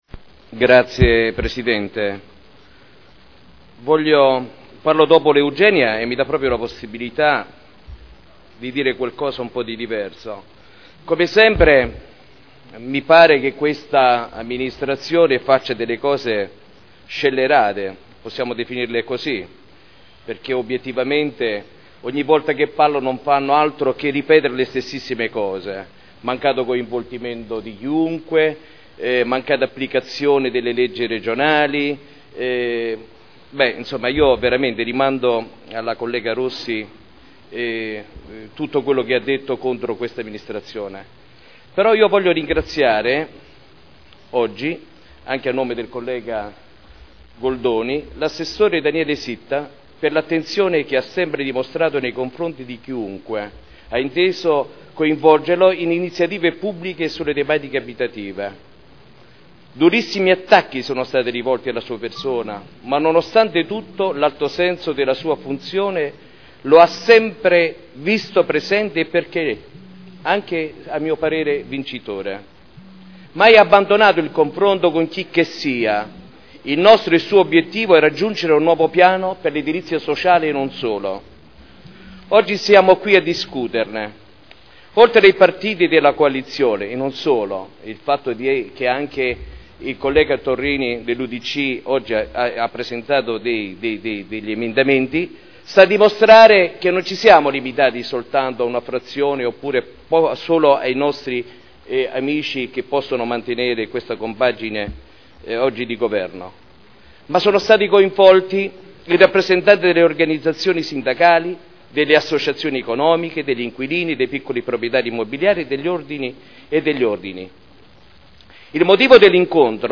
Maurizio Dori — Sito Audio Consiglio Comunale